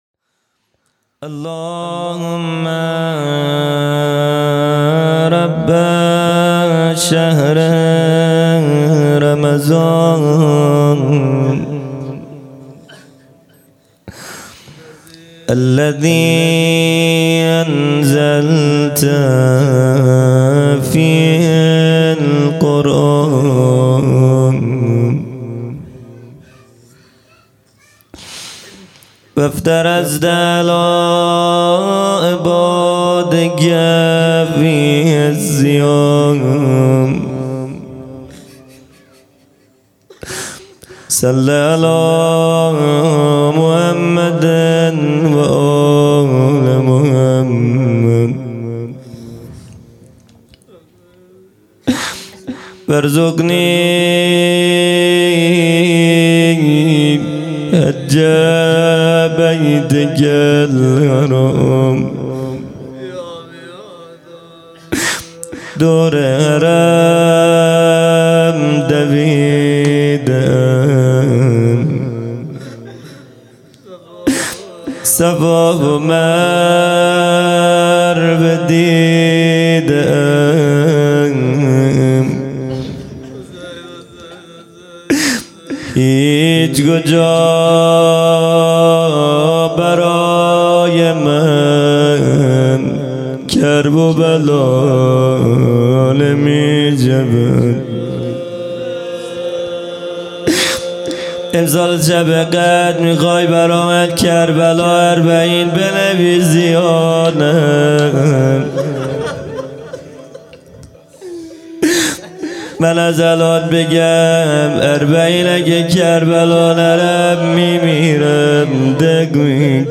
خیمه گاه - هیئت بچه های فاطمه (س) - روضه
جلسۀ هفتگی